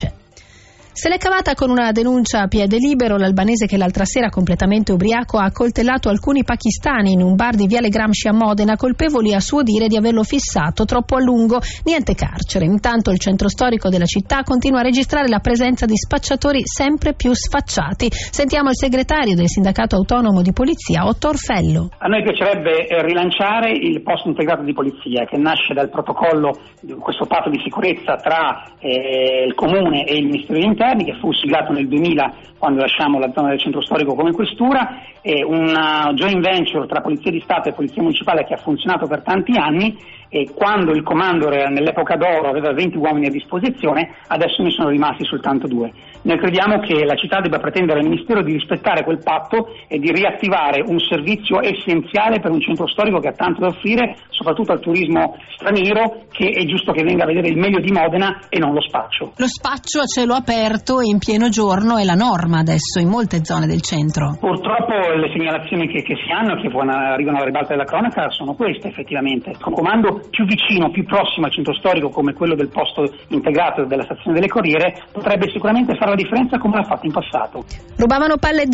INTERVISTA A RADIO BRUNO